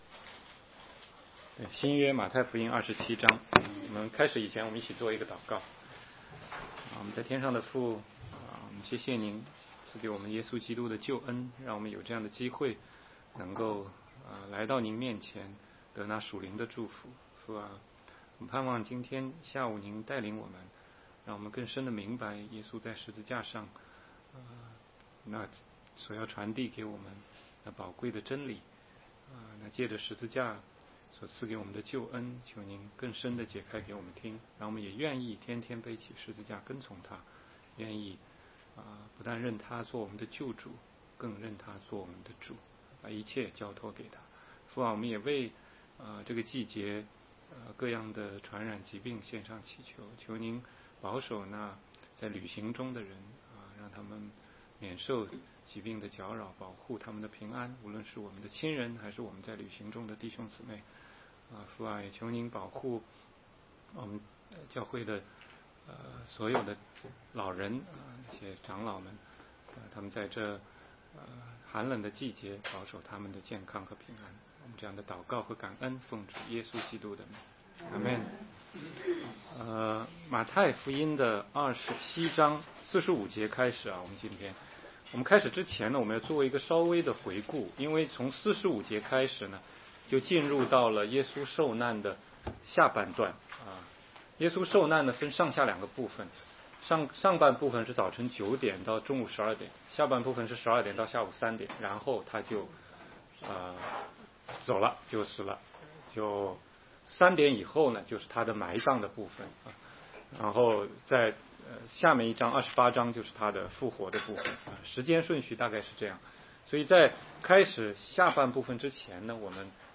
16街讲道录音 - 马太福音27章45-50节：十架七言